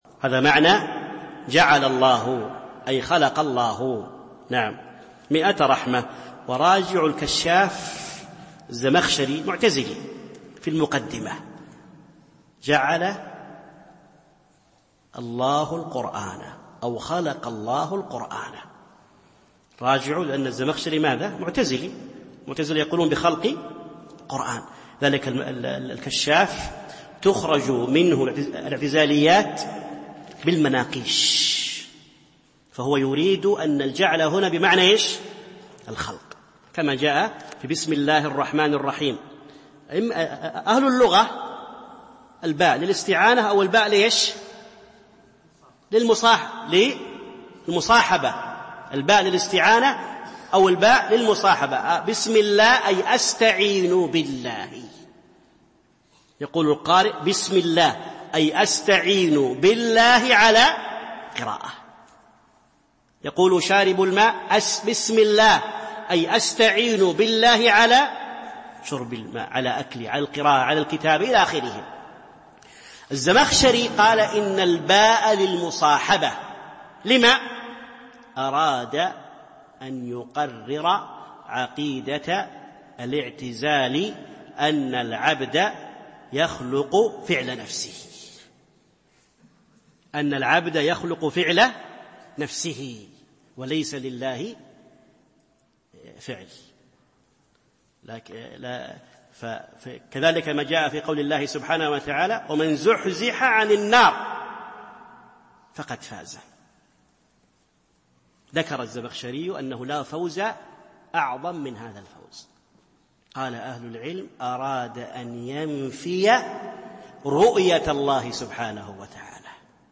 الألبوم: شبكة بينونة للعلوم الشرعية المدة: 4:26 دقائق (1.05 م.بايت) التنسيق: MP3 Mono 22kHz 32Kbps (VBR)